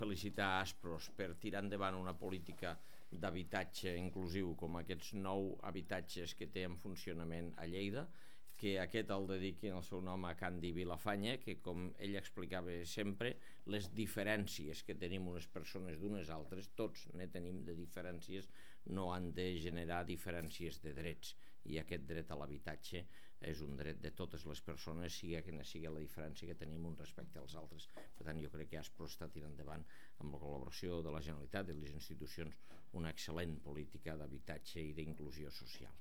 L'alcalde de Lleida, Àngel Ros, ha recordat en la inauguració de la llar Candi Villafañe el tarannà de qui fou regidor i tinent d'alcalde de l'Ajuntament de Lleida en el sentit que va impulsar el reconeixement dels mateixos drets per a tothom.
arxiu-de-so-angel-ros-sobre-els-pisos-daspros-i-el-treball-de-candi-villafane-per-la-igualtat-de-drets